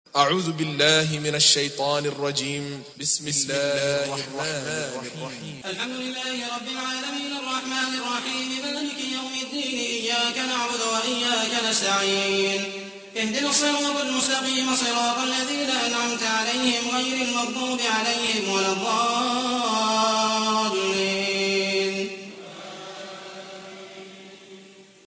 تحميل : 1. سورة الفاتحة / القارئ محمد المحيسني / القرآن الكريم / موقع يا حسين